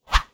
Swing On Air
Close Combat Swing Sound 36.wav